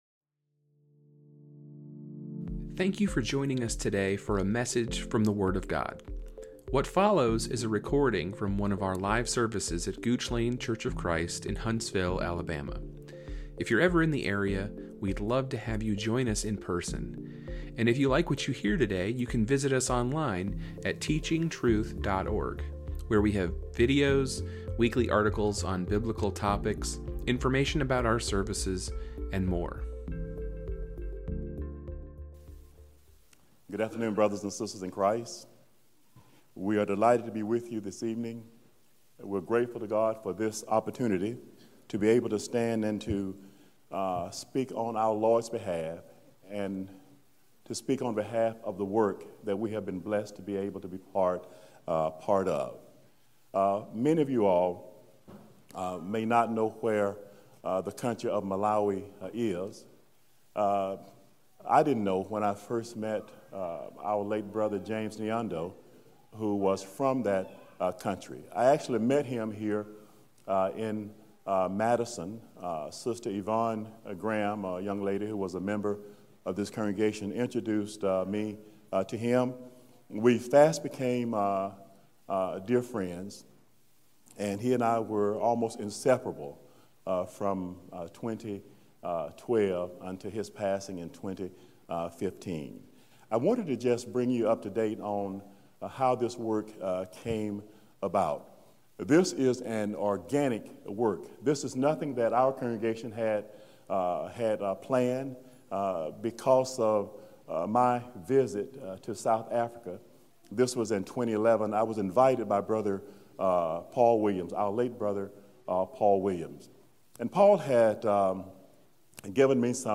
A report on the evangelism work in Malawi